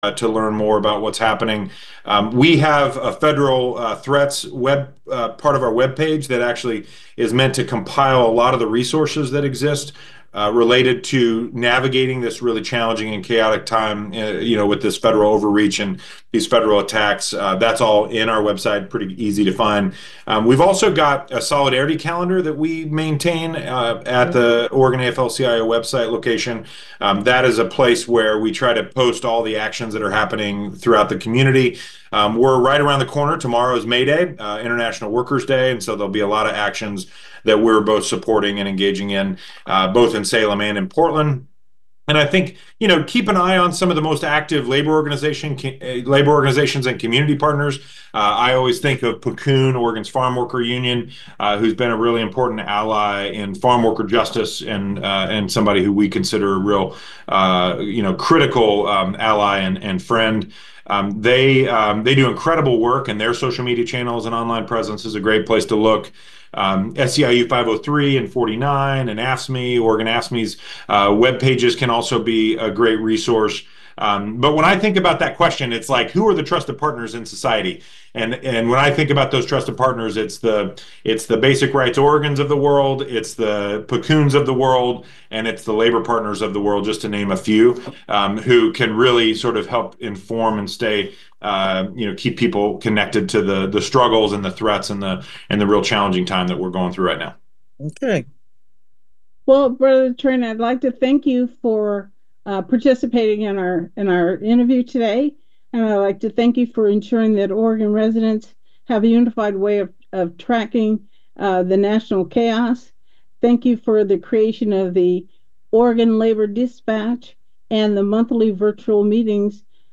This Monday on Prison Pipeline, we bring you an important conversation about a groundbreaking new partnership between Saprea and Edovo that’s delivering trauma recovery resources to incarcerated survivors of child sexual abuse across the U.S.